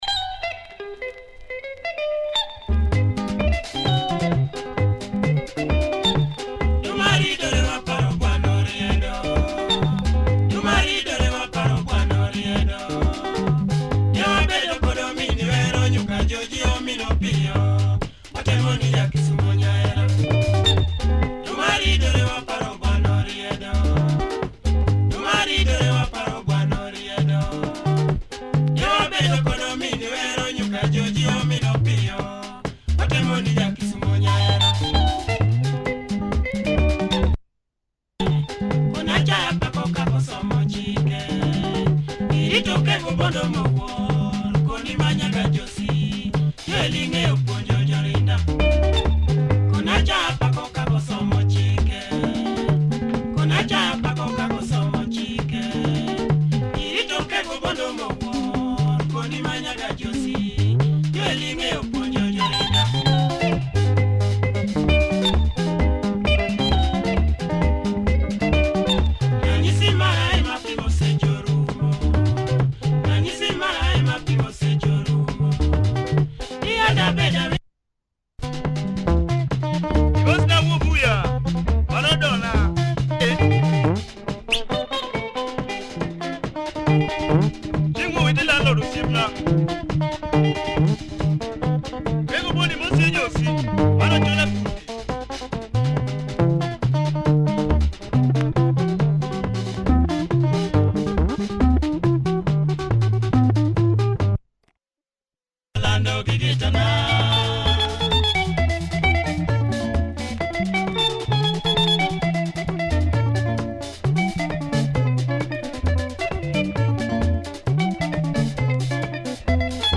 Nice party luo benga, good production, check audio! https